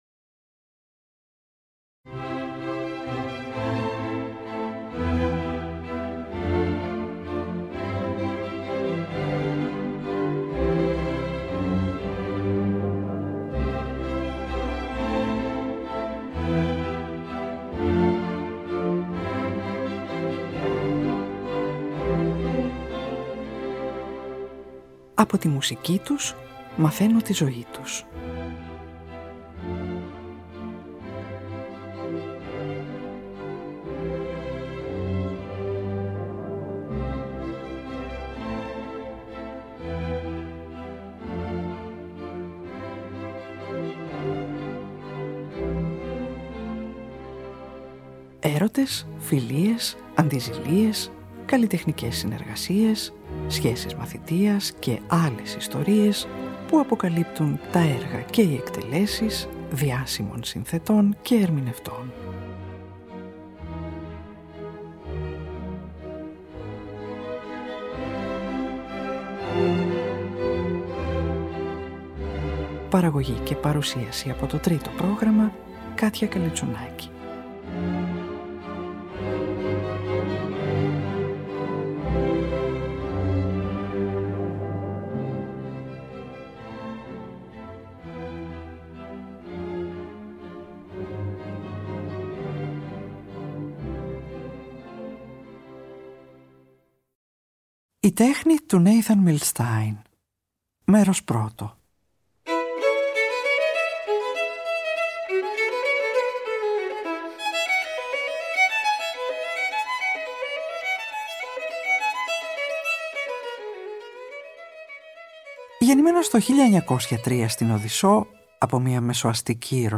από ζωντανή ηχογράφηση του 1957 στη Ν.Υόρκη